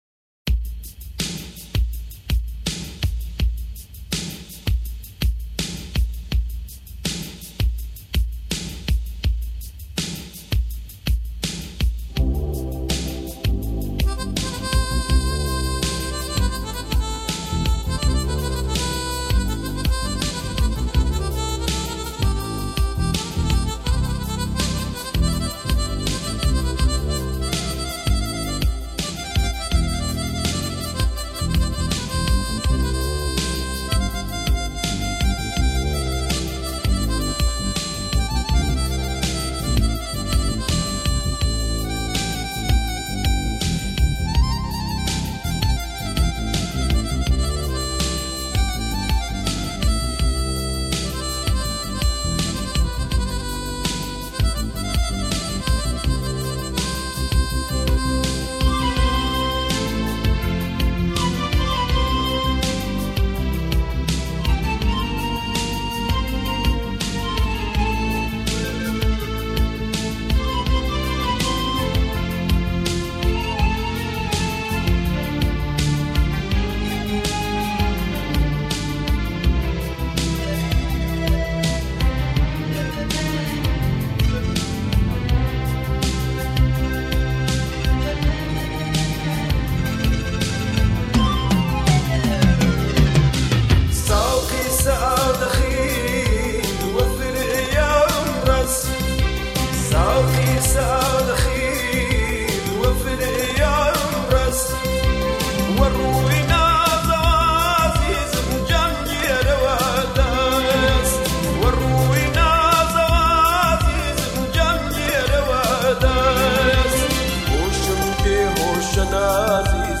آهنگ کردی جدید